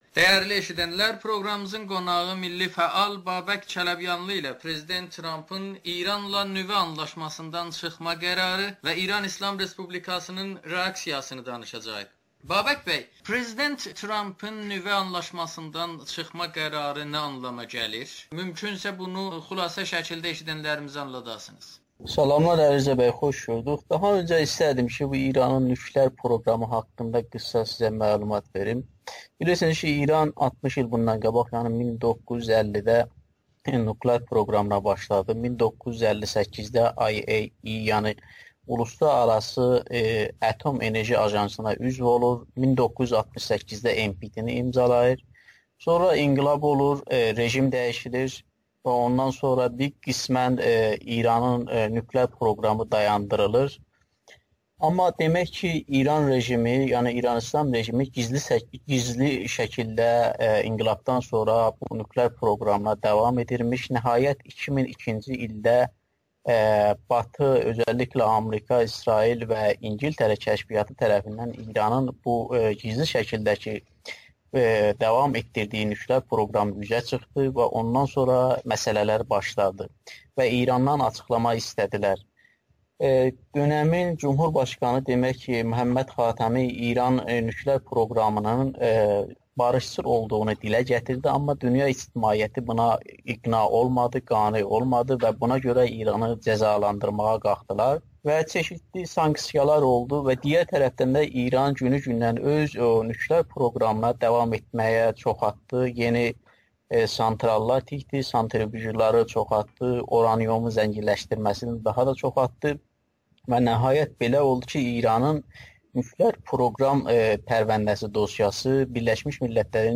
ABŞ-ın nüvə sazişindən çıxması İranda iqtisadi durumu gərginləşdirəcək [Audio-Müsahibə]